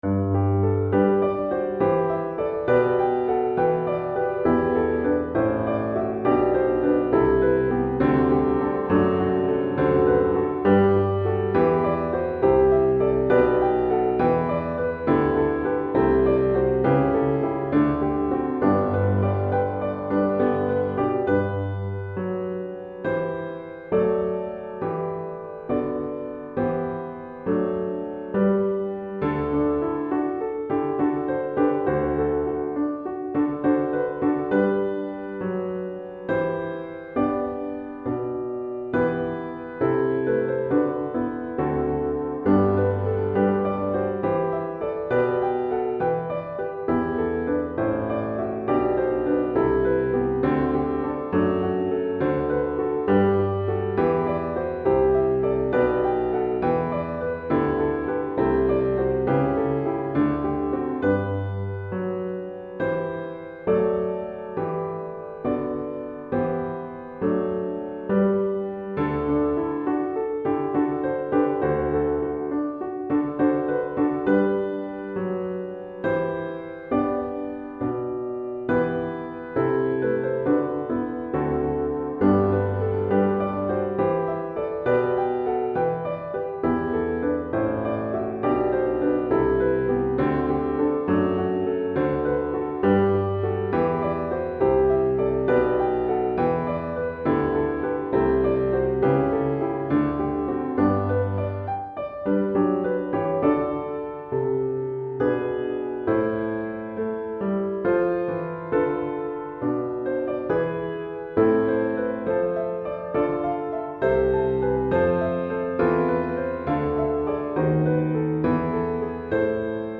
Répertoire pour Piano